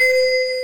MARIM LOOP-R.wav